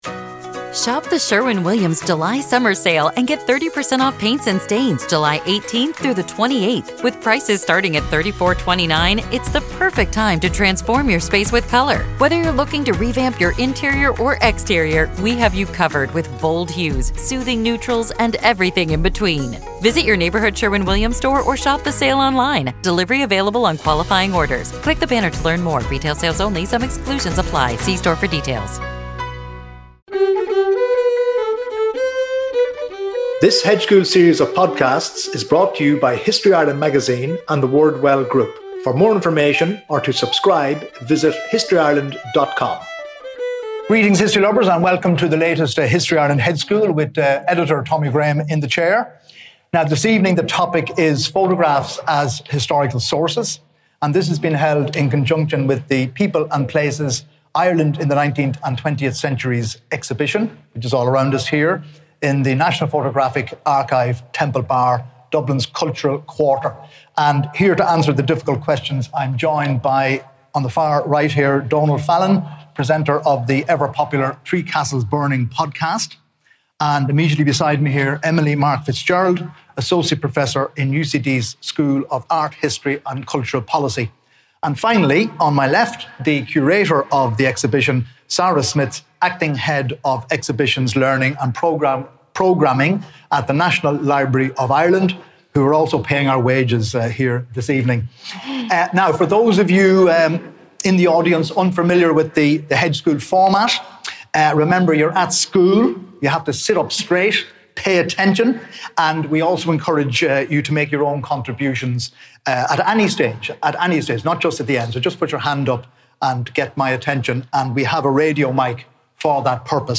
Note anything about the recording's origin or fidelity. (Recorded at the National Photographic Archive, Temple Bar on the 31 May 2023)